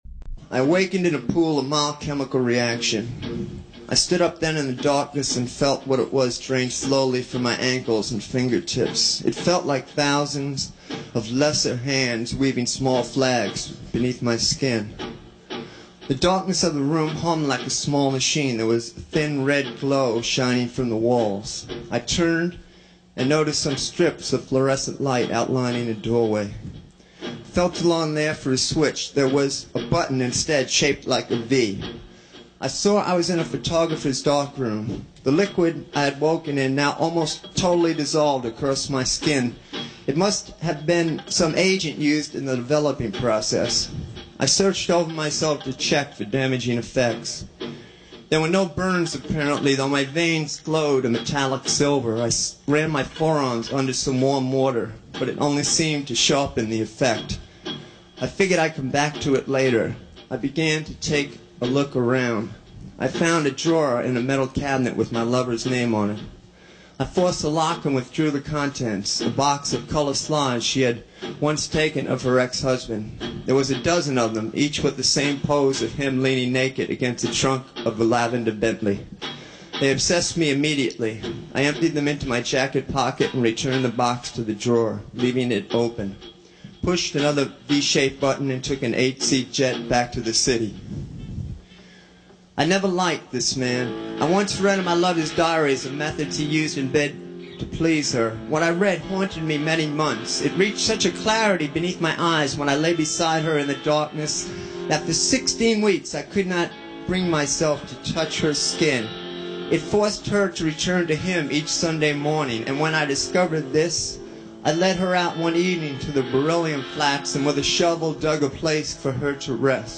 It seems almost inconceivable in the current climate, but back in 1990, a major label – Atlantic Records – actually released an entire album of spoken word performances, the central …
soundbites-from-the-counterculture_09-jim-carroll.mp3